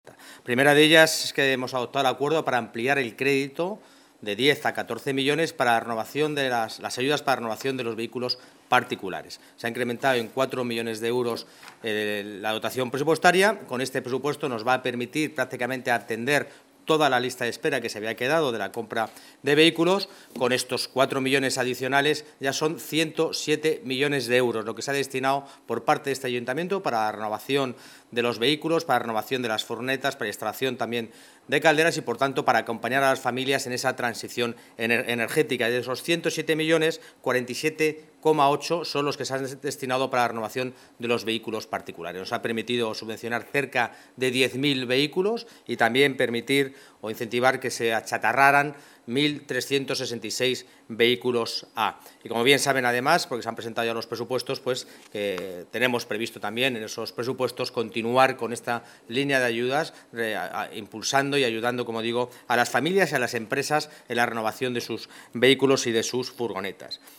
Nueva ventana:El delegado de Urbanismo, Medioambiente y Movilidad, Borja Carabante